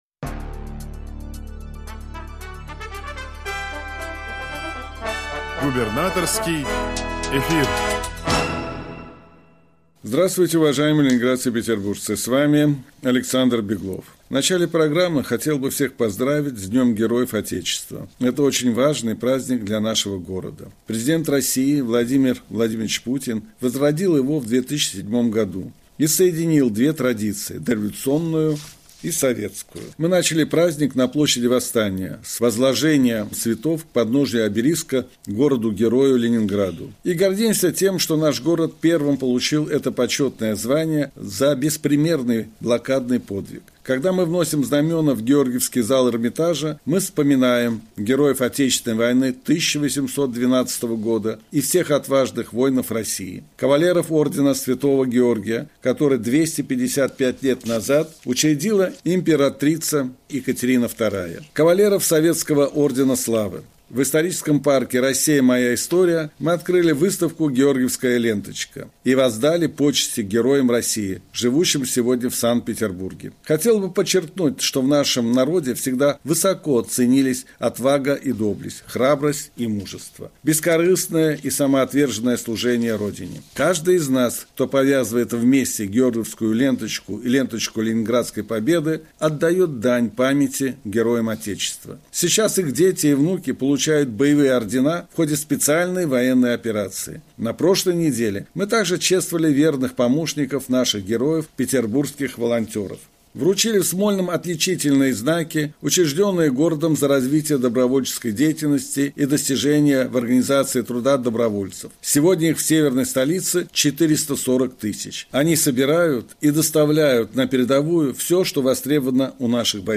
Радиообращение – 9 декабря 2024 года